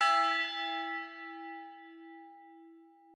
bell1_1.ogg